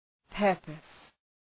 Προφορά
{‘pɜ:rpəs}
purpose.mp3